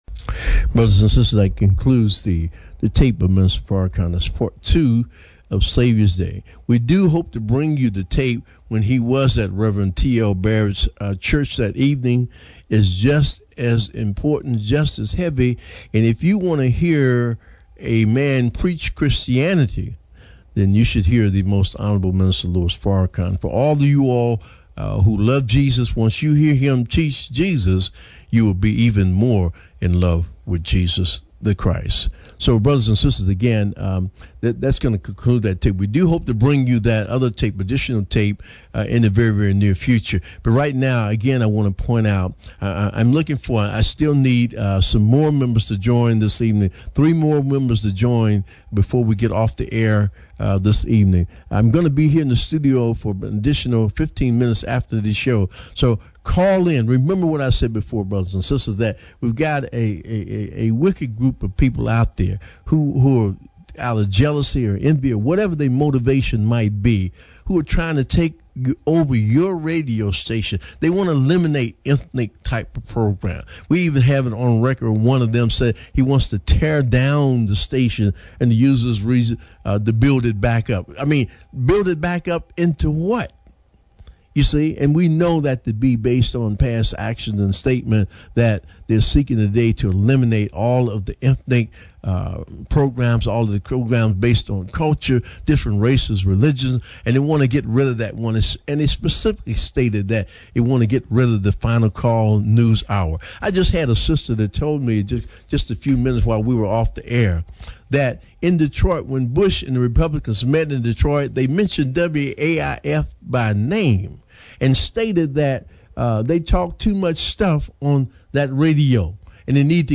ON THE AIR.